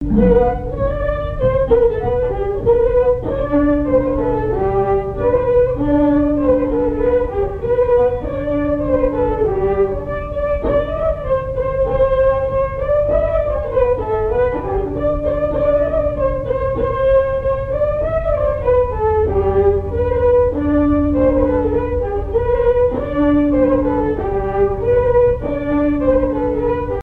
Polka : Piquons, belle Madeleine
Chants brefs - A danser
polka piquée
Airs à danser aux violons et deux chansons
Pièce musicale inédite